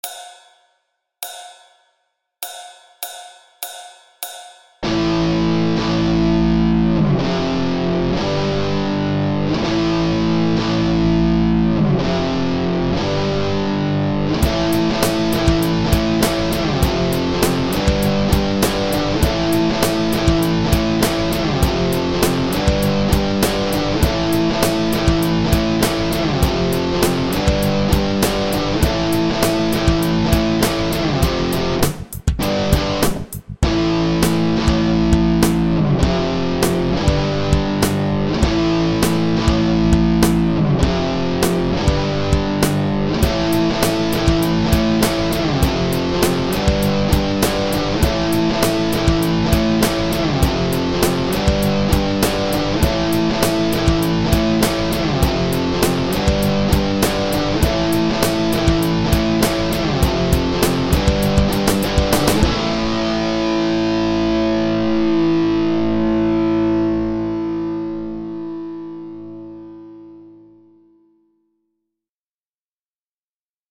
Here is the jam track I've used in the demo:
This track consists of a very simple power chord progression that will be appropriate for almost any guitar solo:
A5 x2 → F5 → G5
This lick sounds especially impressive in the beginning of the jam track, right after the hi hat intro beats.
Am_F_G_accompaniment.mp3